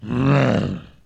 zombie-roar-5.wav